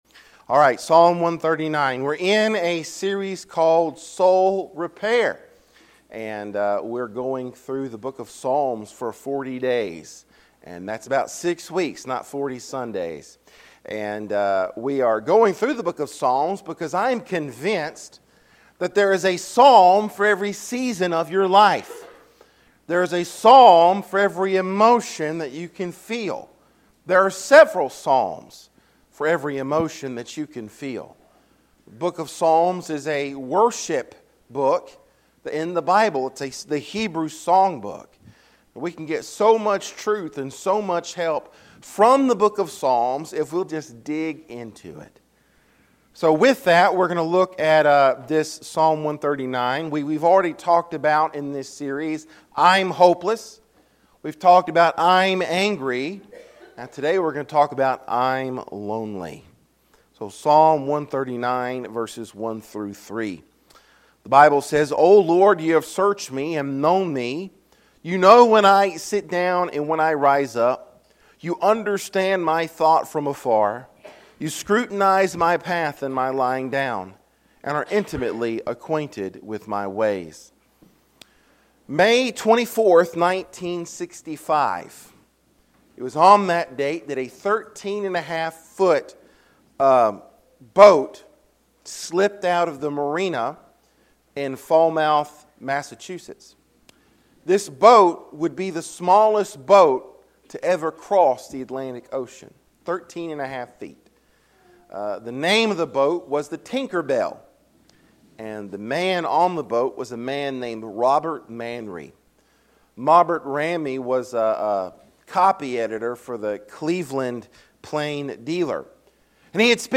Sermons | Rocky Point Baptist Church